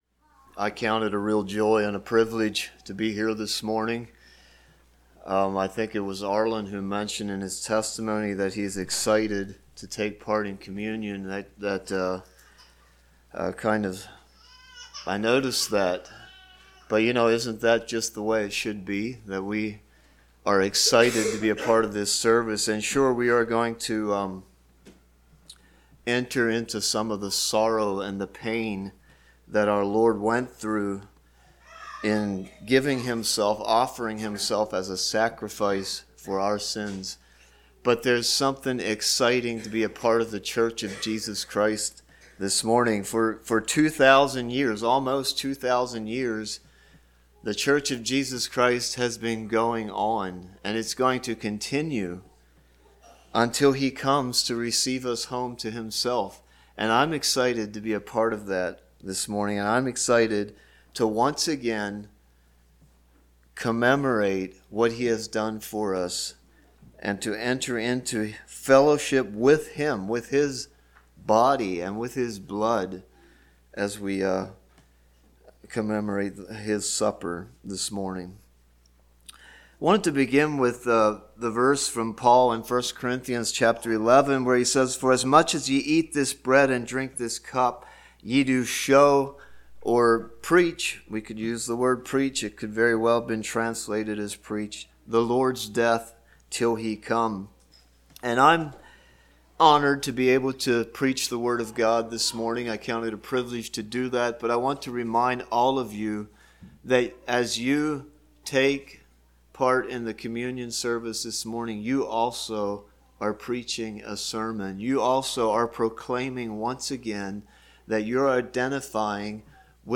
Passage: Matthew 26:29-46 Service Type: Sunday Morning Topics: Communion , Jesus « A Broken and Contrite Heart